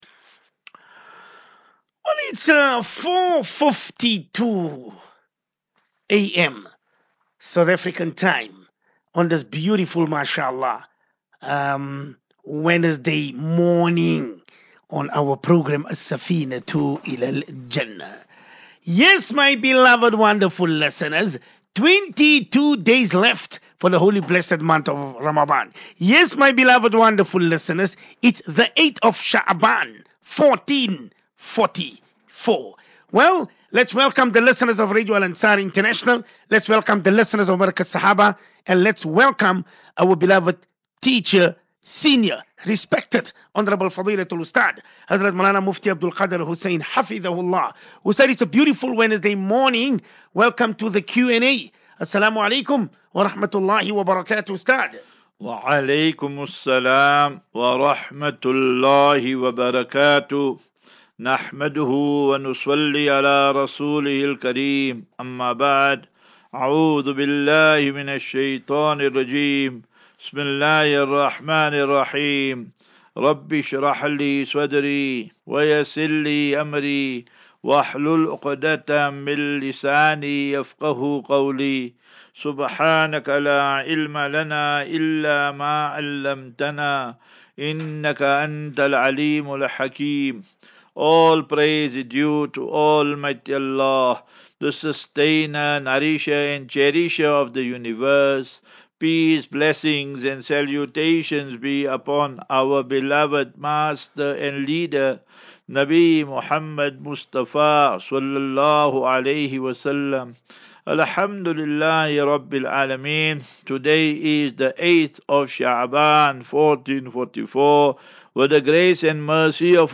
View Promo Continue Install As Safinatu Ilal Jannah Naseeha and Q and A 1 Mar 01 Mar 23 Assafinatu Illal Jannah 35 MIN Download